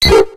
PIDGEY.ogg